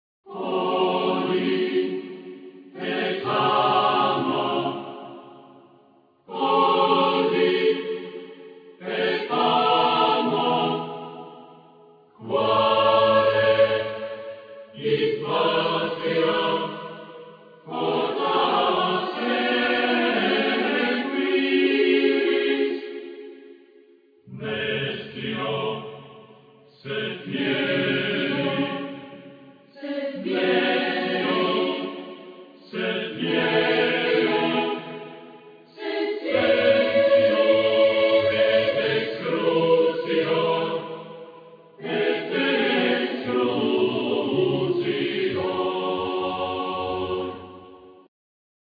Keyboards,Sampler,Kokle,Fiddle,Vocal and naration
Guitars
Accoustic guitar
French horn